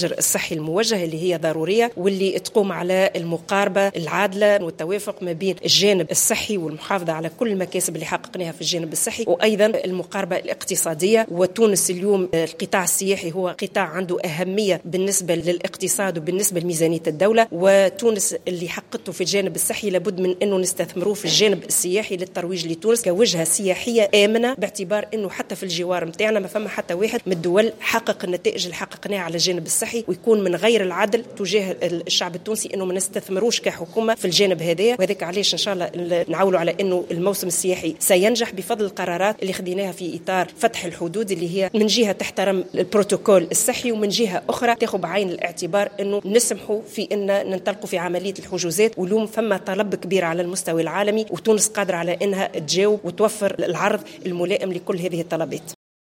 على هامش انطلاق الندوة الوطنية للمديرين الجهويين لشؤون المراة والاسرة بالحمامات
أسماء السحيري الناطقة الرسمية باسم الحكومة